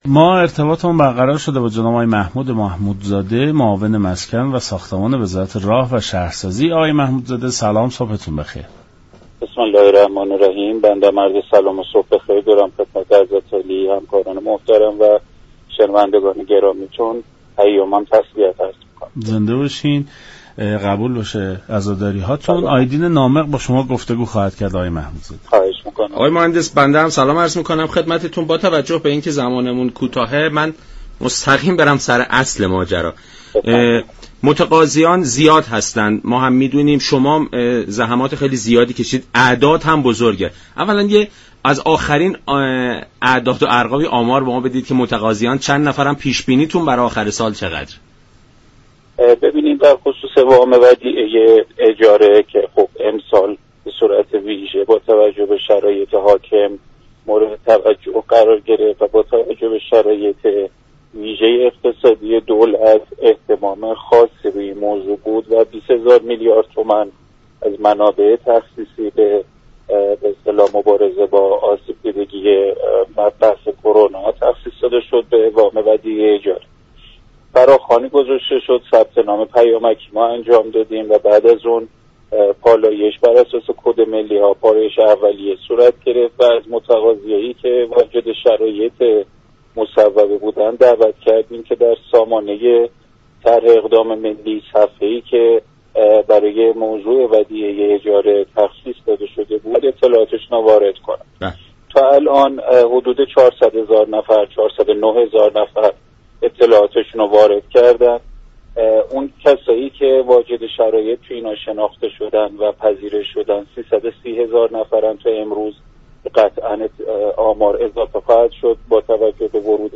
به گزارش شبكه رادیویی ایران، محمود محمودزاده معاون ساختمان و مسكن وزارت راه و شهرسازی در برنامه سلام صبح بخیر رادیو ایران درباره وام ودیعه اجاره مسكن گفت: امسال با توجه به شرایط ویژه اقتصادی، دولت با اخذ 20 هزار میلیارد تومان از منابع تخصیصی و ارائه وام ودیعه اجاره مسكن به مبارزه با آسیب دیدگی ویروس كرونا پرداخته است.